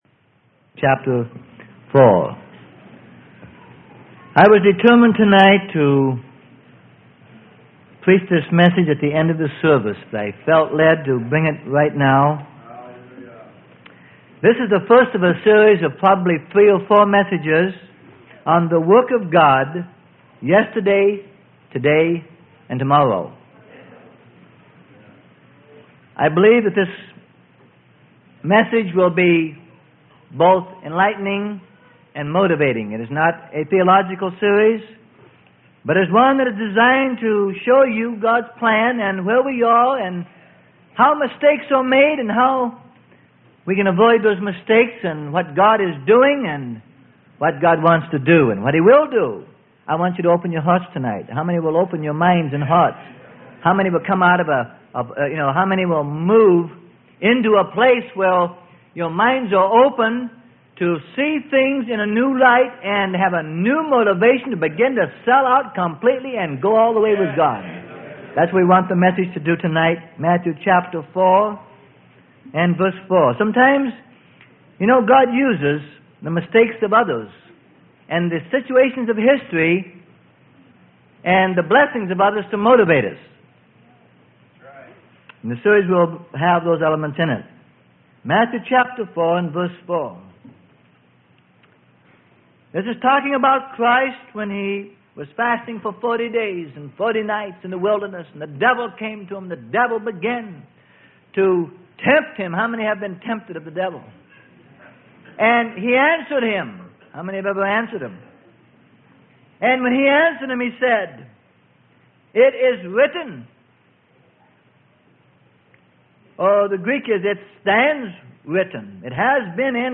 Sermon: The Work Of God, Yesterday, Today, And Tomorrow Part 1 Of 3.